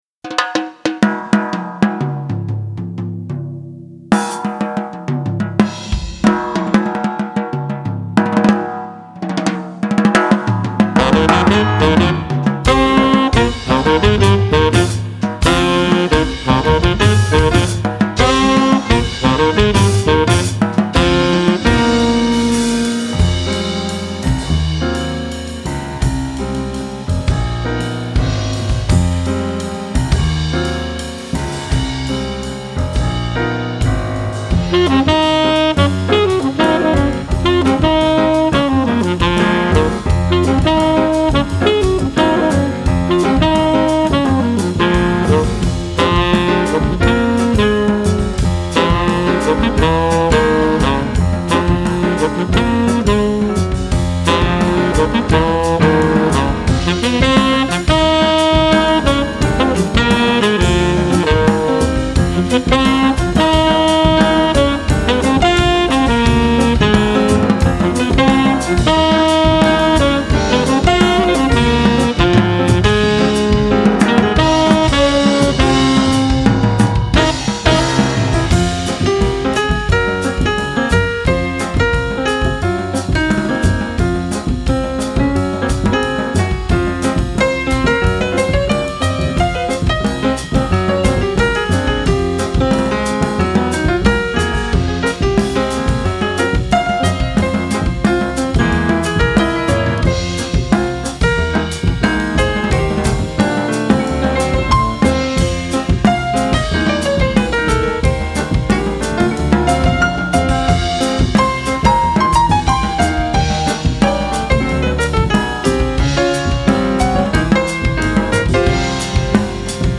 Contemporary Jazz
Piano
Soprano & Tenor Saxophone
Contrabass
Drums